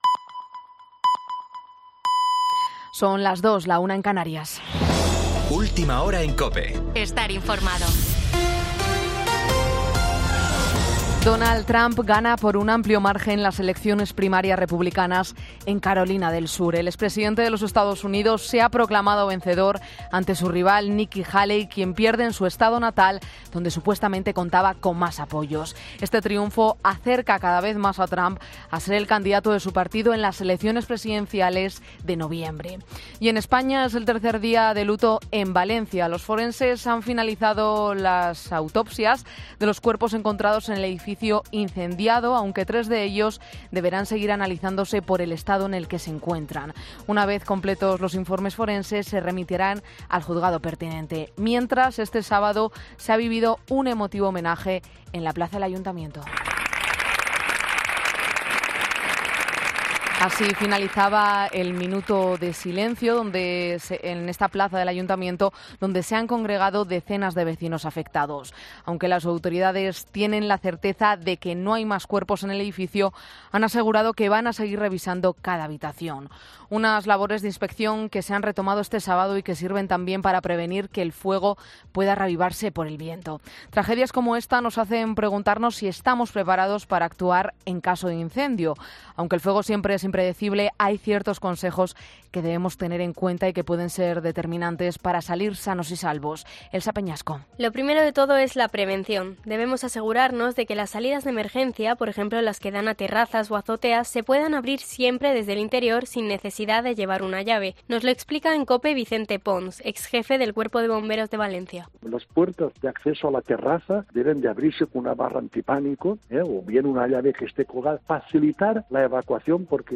Boletín 02.00 horas del 25 de febrero de 2024